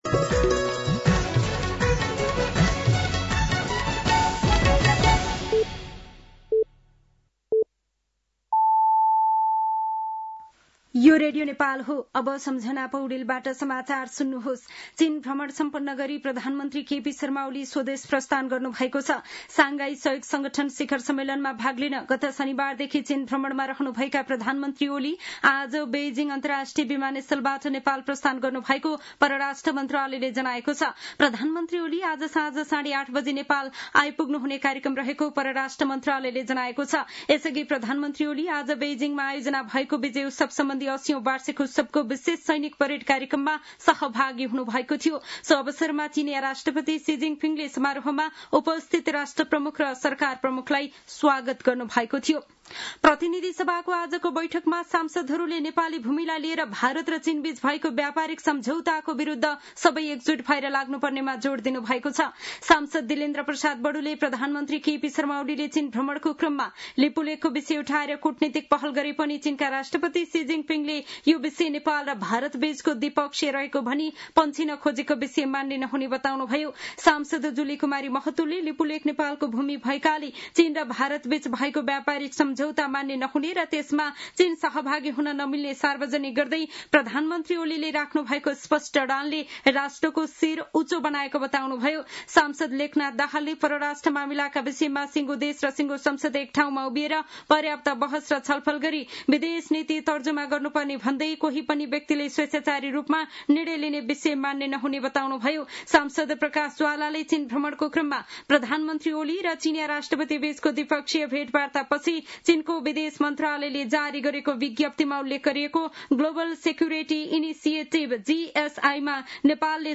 साँझ ५ बजेको नेपाली समाचार : १८ भदौ , २०८२